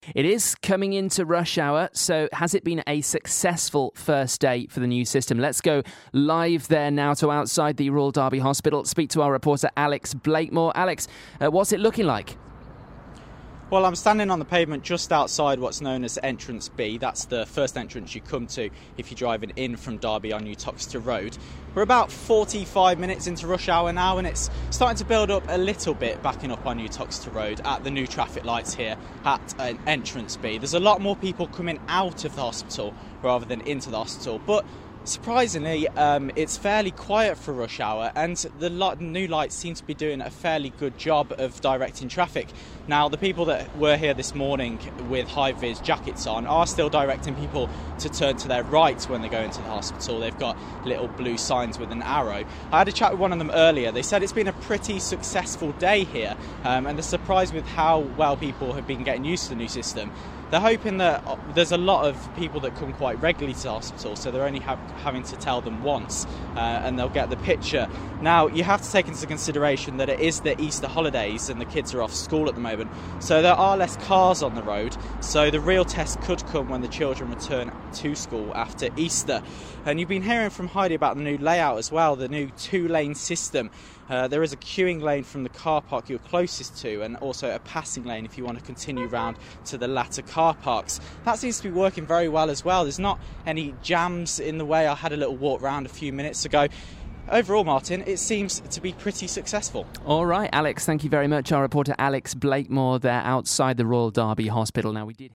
Live report from Royal Derby Hospital with an update on the new one way system around their loop road.